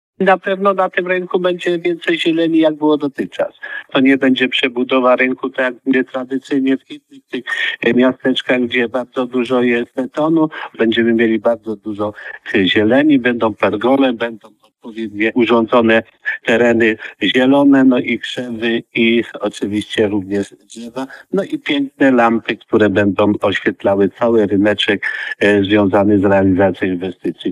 O tym co jeszcze, mówi burmistrz Wilamowic Marian Trela.